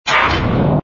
engine_ci_fighter_start.wav